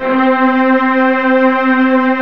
DIRT STRING.wav